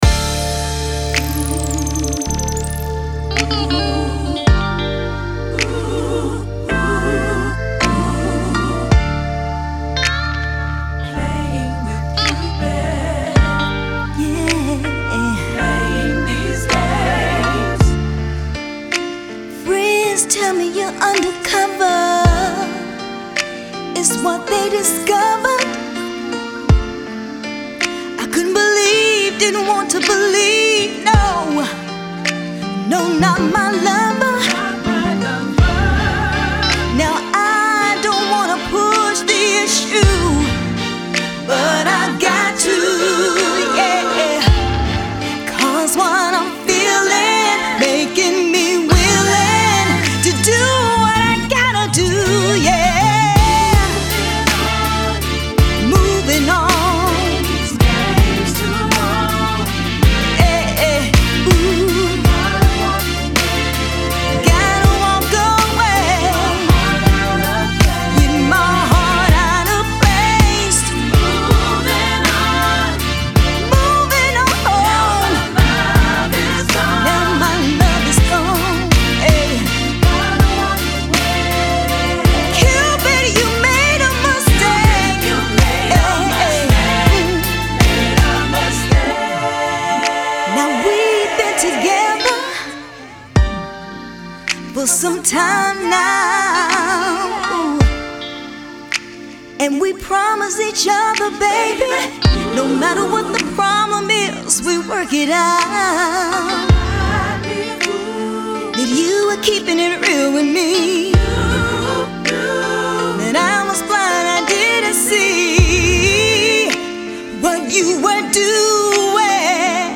a sassy r&b groove
RnB